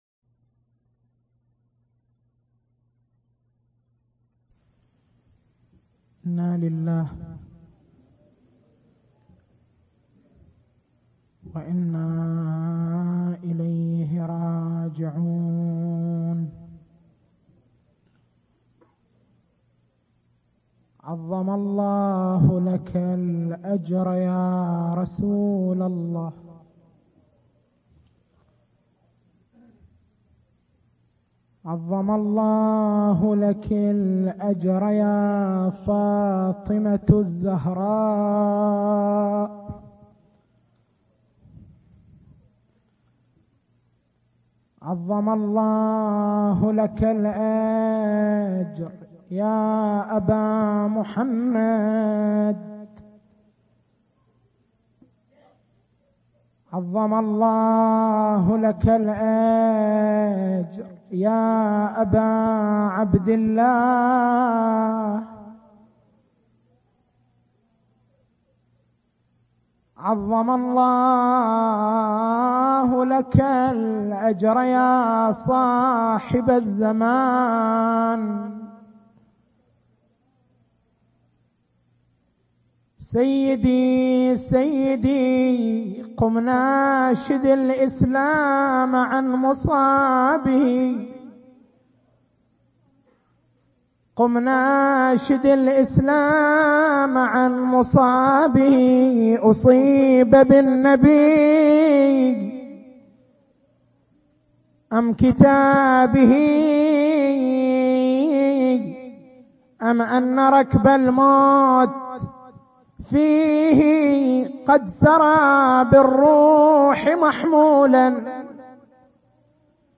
تاريخ المحاضرة: 21/09/1418